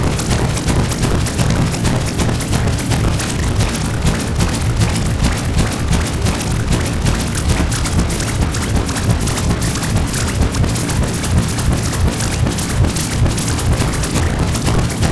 • organic toy texture fractured.wav